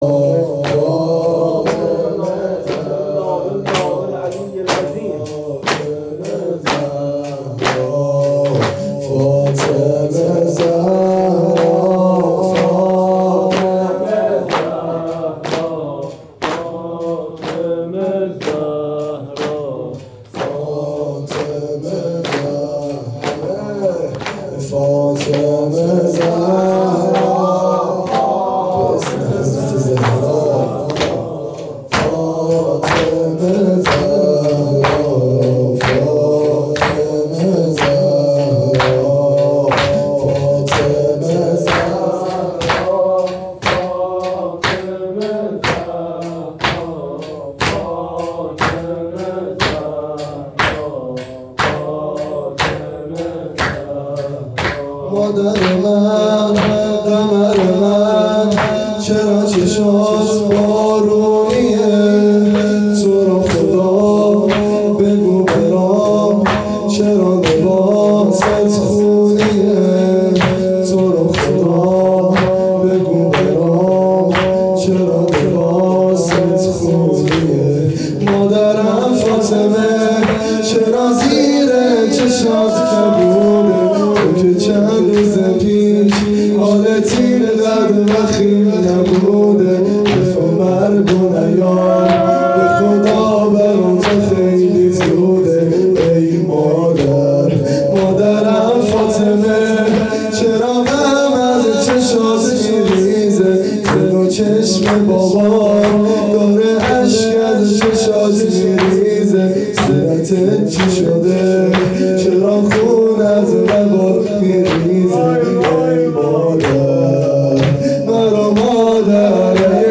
مراسم هفتگی۹۳/۱۱/۱۵
روضه حضرت زهرا(سلام الله علیها)